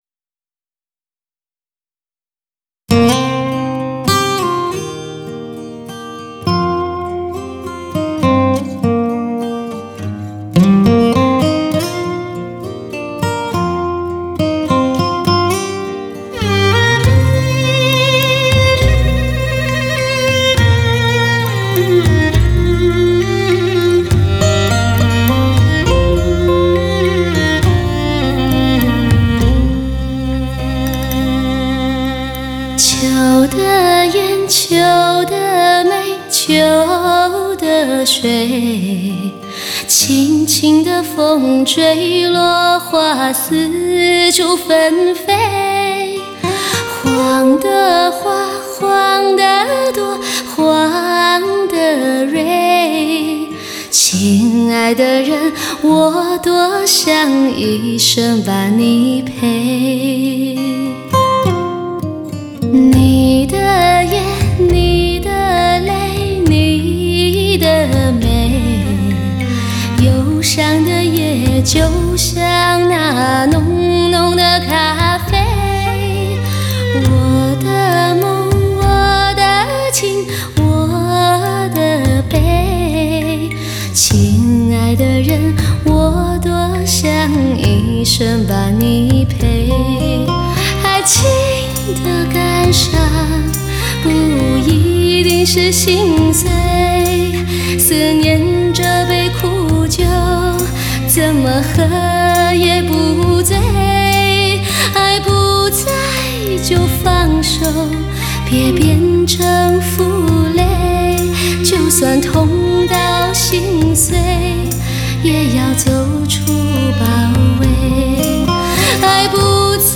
震天动地，强效动感，优美动人的完美旋律。
女声低音炮，发挥音乐的极致，最值得收藏的音乐极品。
源自心灵深处的倾诉，诠释属于梦的境界，音色淳厚优美，典雅华丽而委婉。